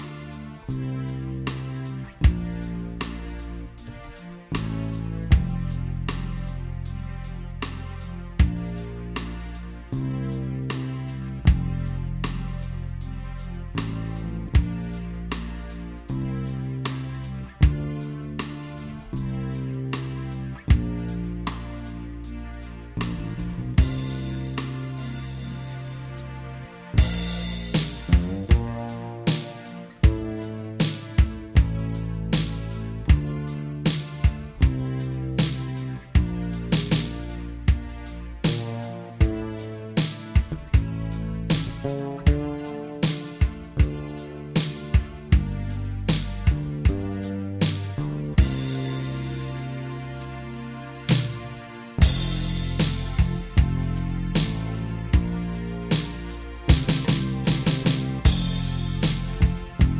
Minus Main Guitars For Guitarists 4:10 Buy £1.50